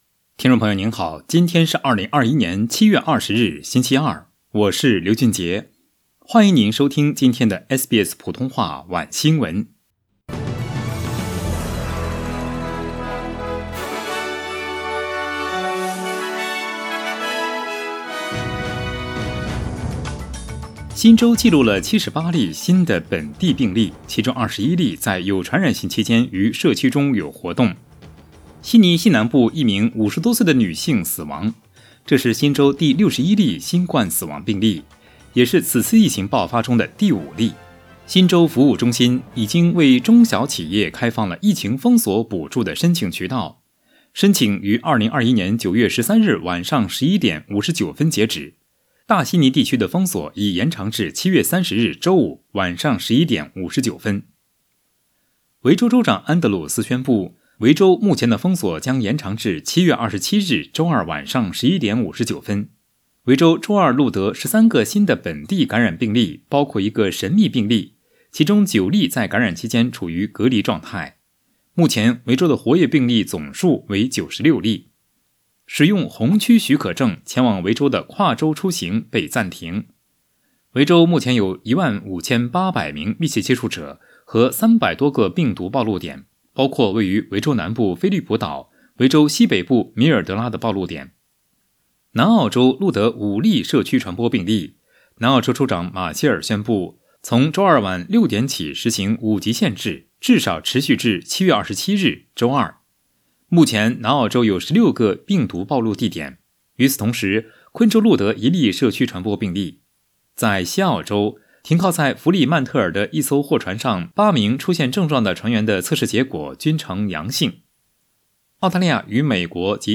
SBS Mandarin evening news Source: Getty Images